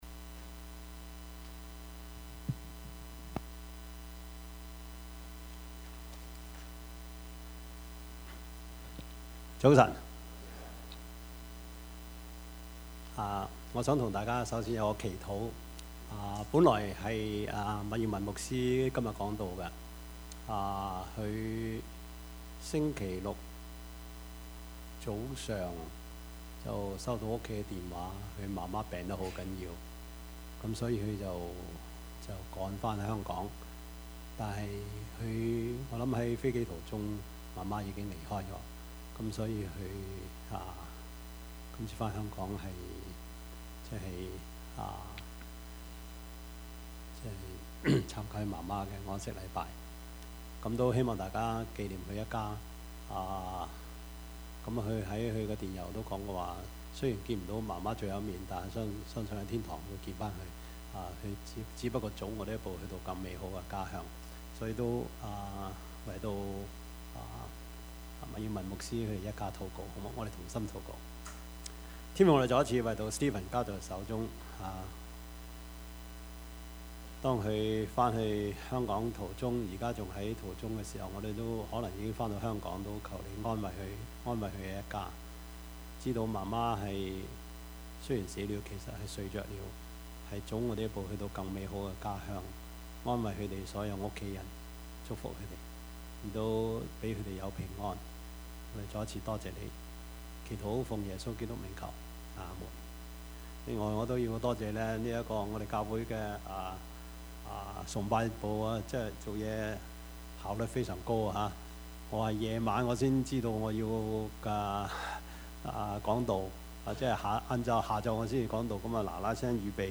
Service Type: 主日崇拜
Topics: 主日證道 « 阿媽唔易做 神蹟奇事 »